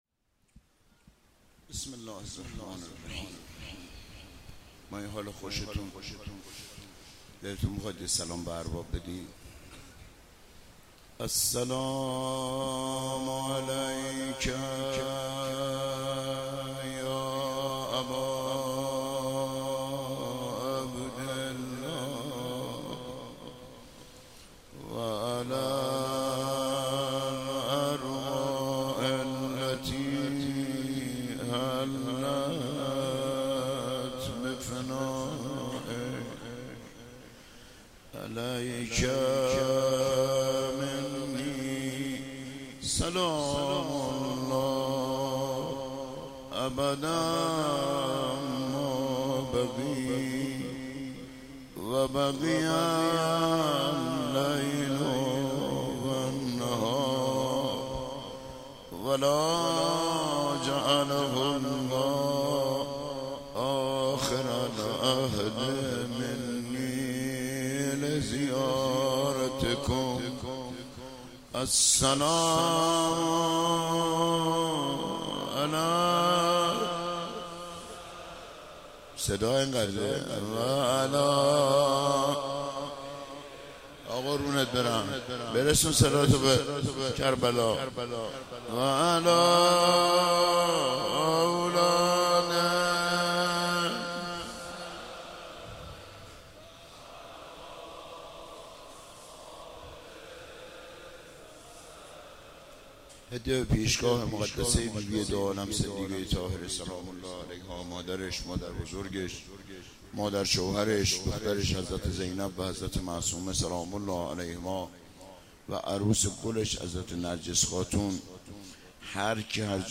شب دوم محرم 96 - روضه - ای خانه وصال خدا